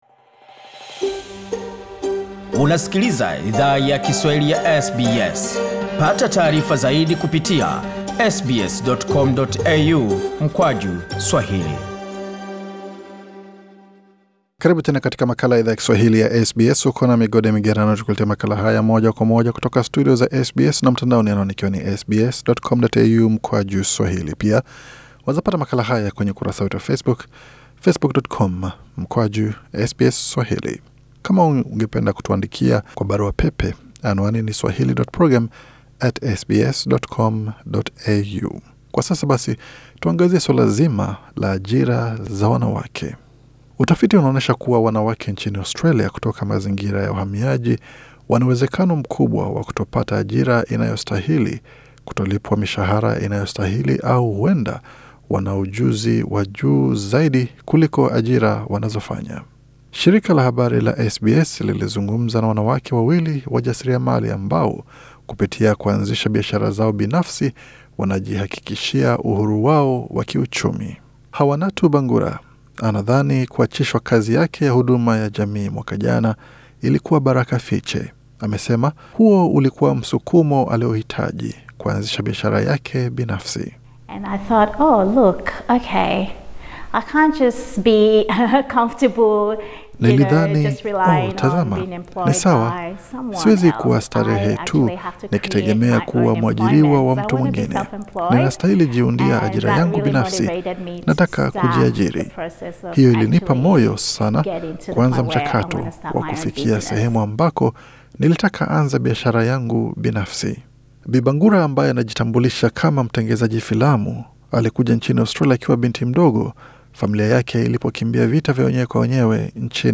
Shirika la habari la SBS lilizungumza na wanawake wawili wajasiriamali ambao, kupitia kuanzisha biashara zao binafsi wana jihakikishia uhuru wao wakiuchumi.